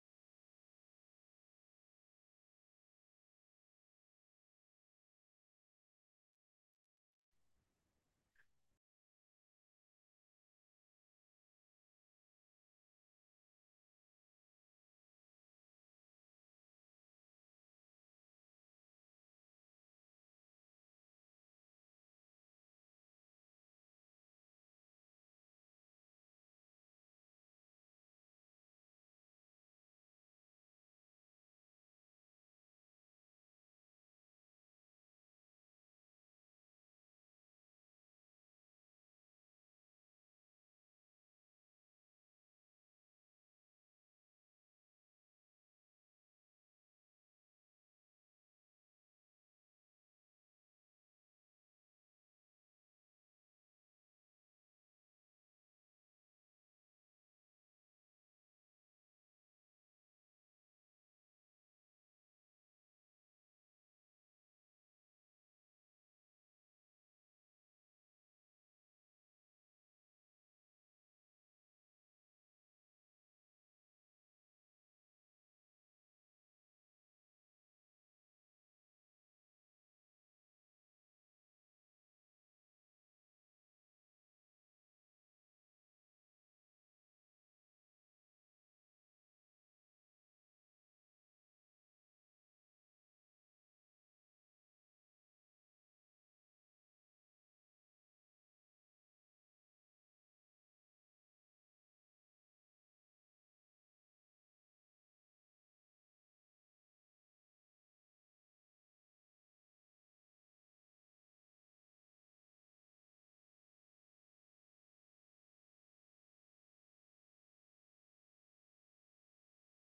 Starting in DECEMBER, Board meetings are held every 3rd Thursday of the month at 6pm via Zoom Conferencing
Board Meetings - Moved back to 6PM Meeting